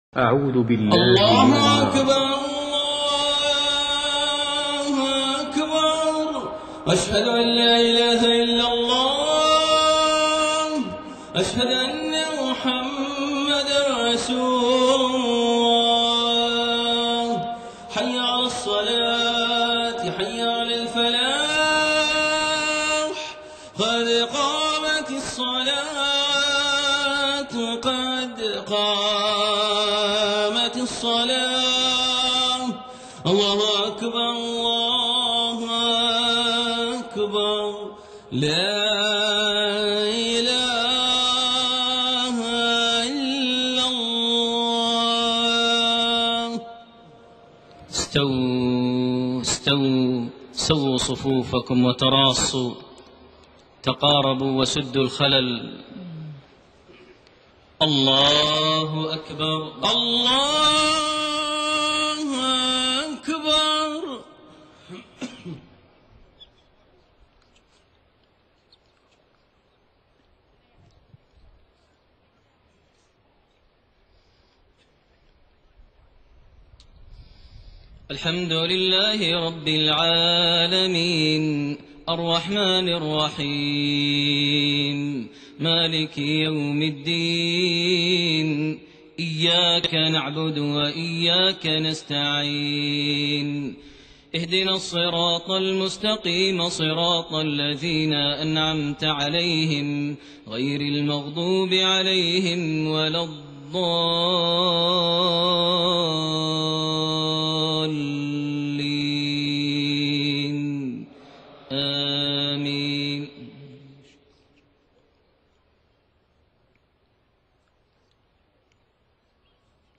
صلاة المغرب6-8-1428 من سورة مريم 83-98 > 1428 هـ > الفروض - تلاوات ماهر المعيقلي